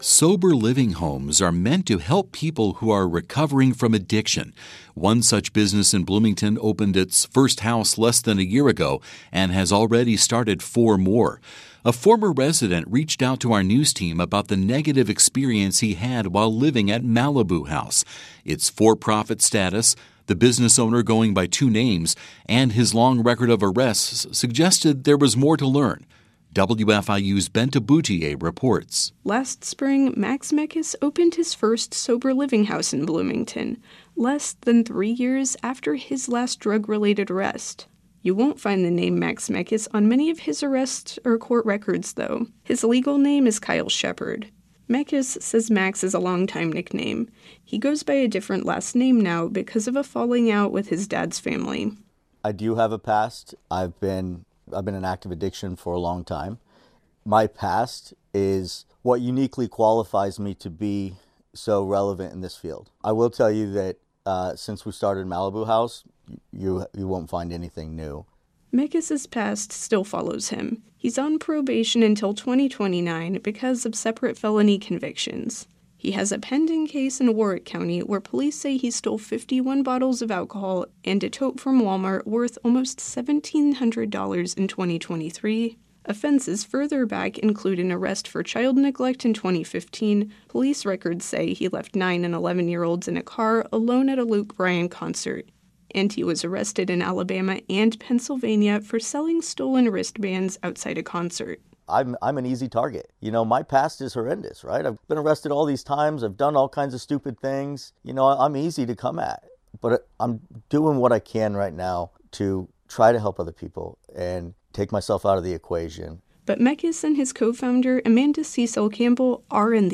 In an interview with WTIU/WFIU News